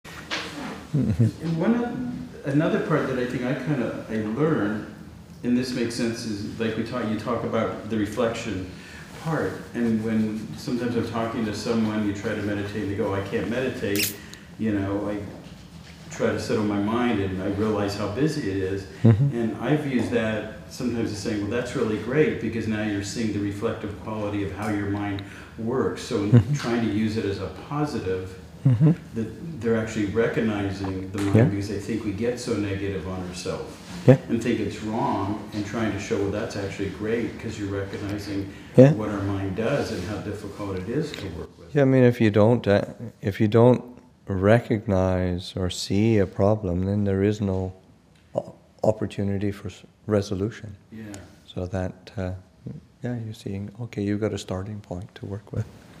Calming the Busy Mind, Session 2 – Aug. 11, 2013